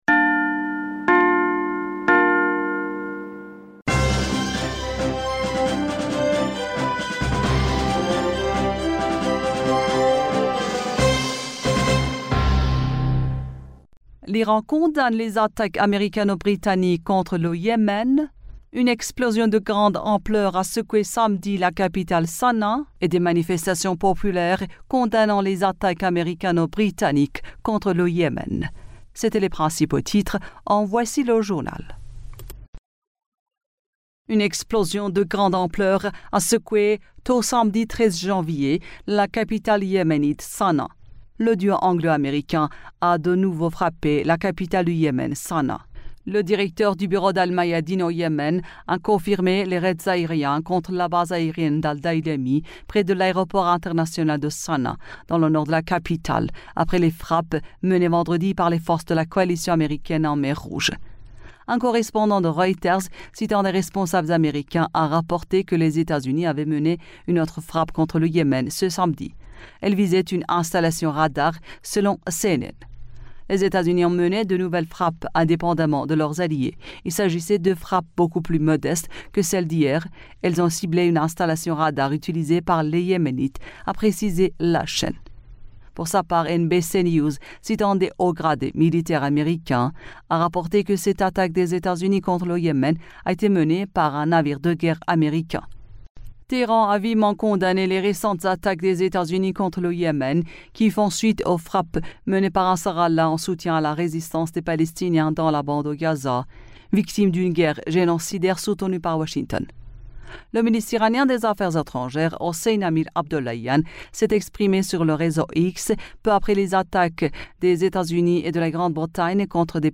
Bulletin d'information du 13 Janvier 2024